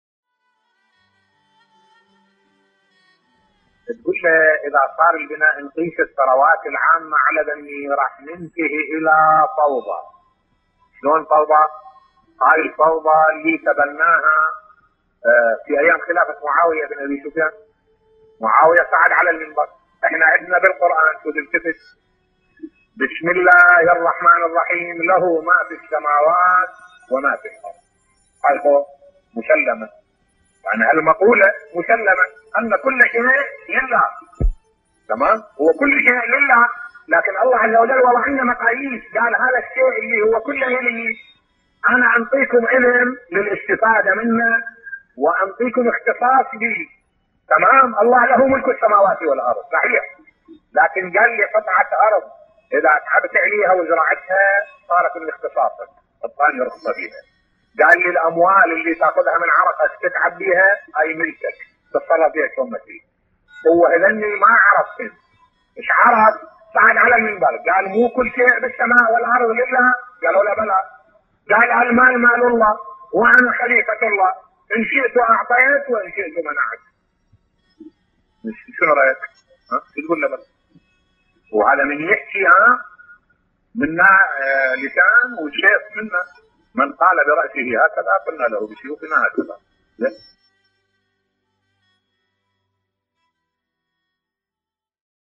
ملف صوتی طغيان معاوية بن ابي سفيان بصوت الشيخ الدكتور أحمد الوائلي